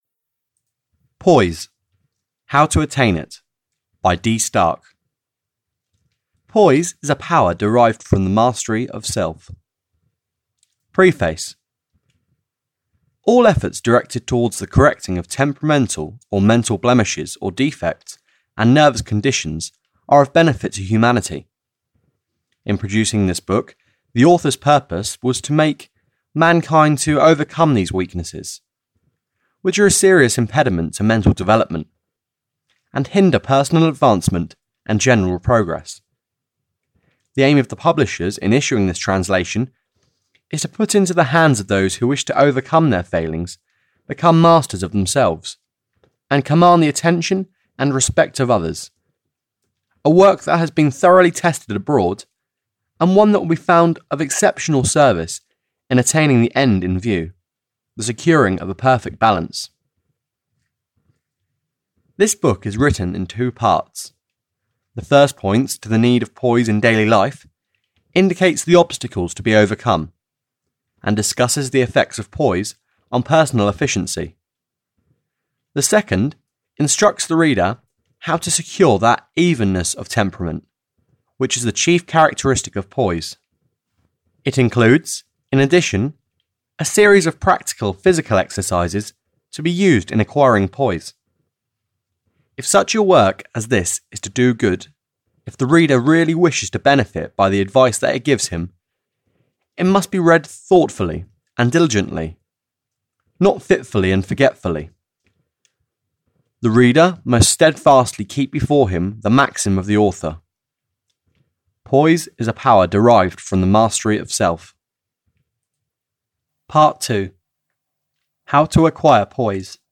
Audio knihaPoise - How To Attain It (EN)
Ukázka z knihy